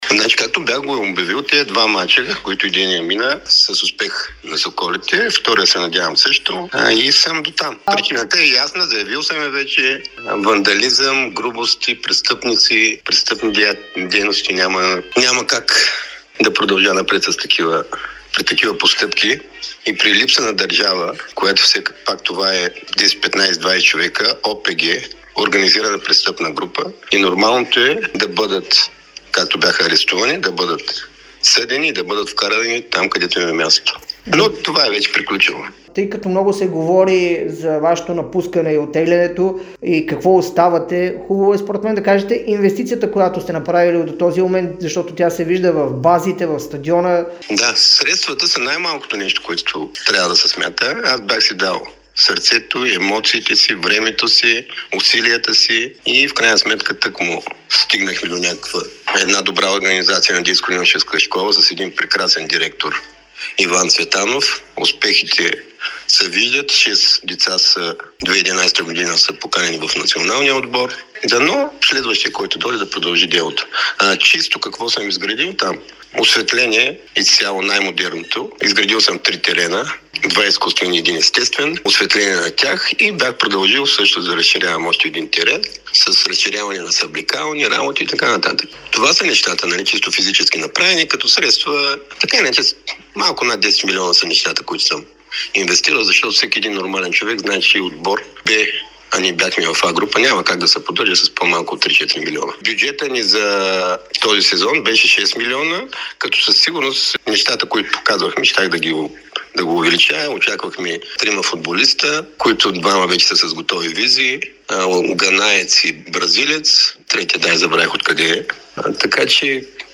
ексклузивно интервю пред Дарик радио и dsport